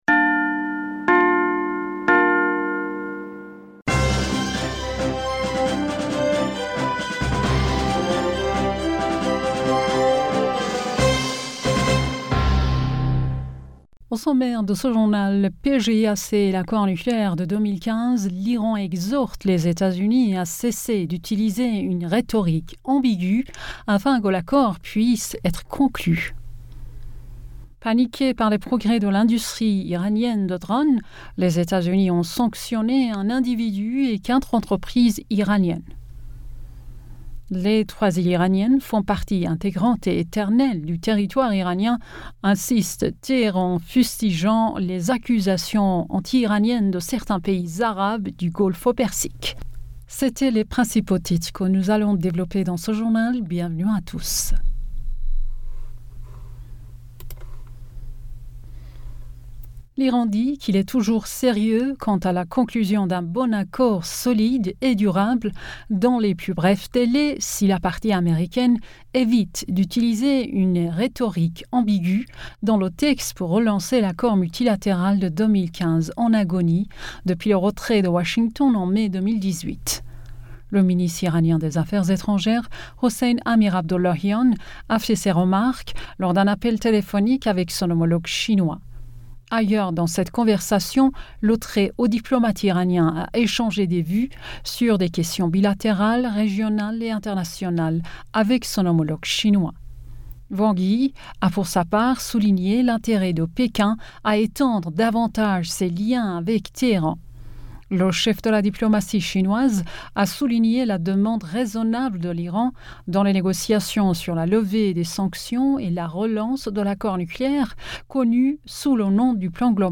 Bulletin d'information Du 09 Septembre